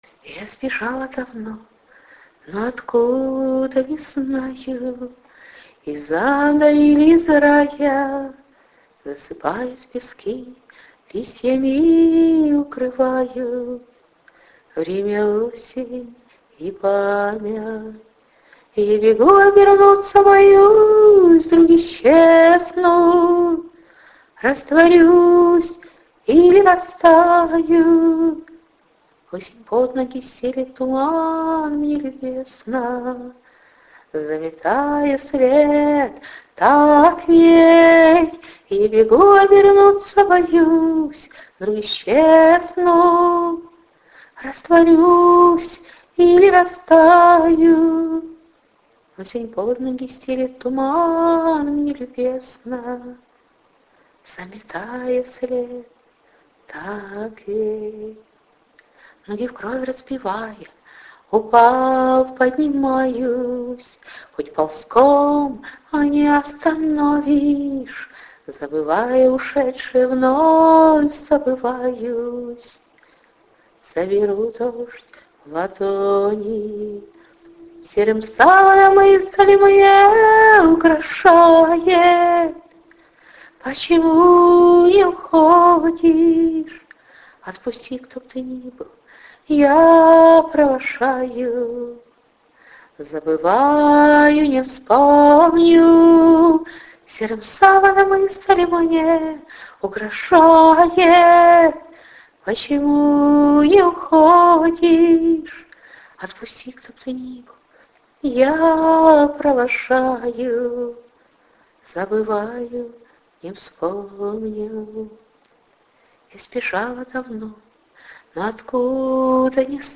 Шикарно звучите, девушка! give_rose
16 16 Согреваешь своим пением,..красиво поешь,..трепетно и нежно! clap flo26